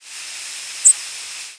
Black-and-white Warbler Mniotilta varia
Flight call description A delicate "dzinn" with a fine sibilant buzz, often slightly rising. Many calls have a subtle two-parted character, while others are nearly monotone.
Fig.1. Maryland September 29, 1994 (MO).
Bird in flight.